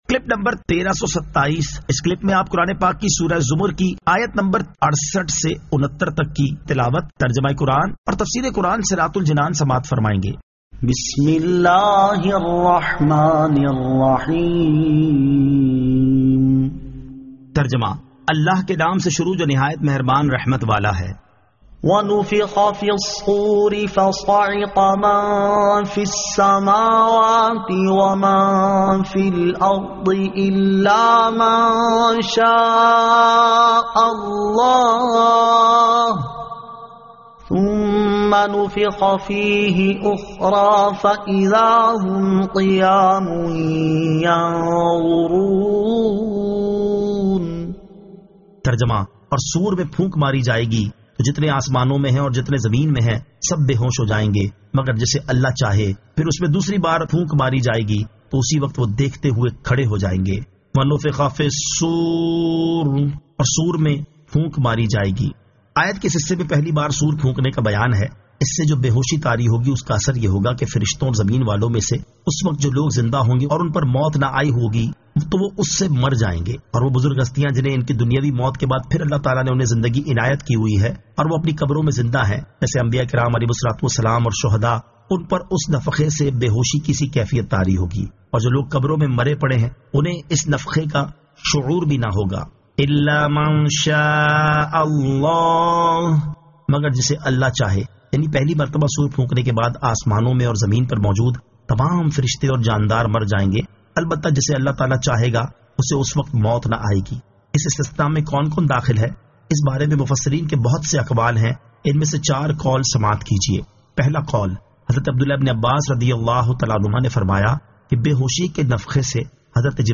Surah Az-Zamar 68 To 69 Tilawat , Tarjama , Tafseer